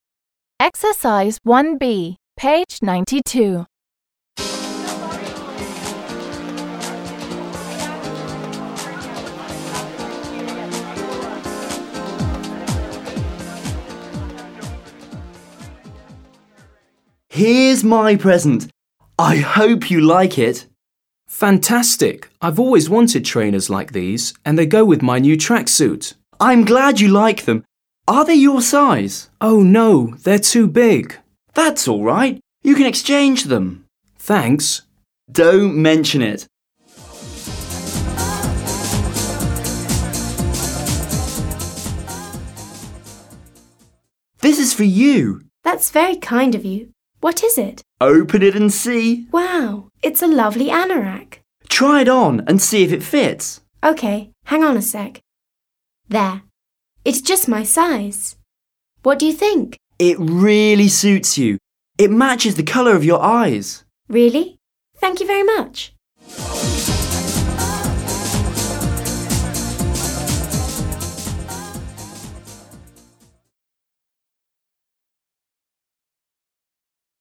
b. The sentences are from two dialogues between an uncle and his nephew and niece.